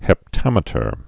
(hĕp-tămĭ-tər)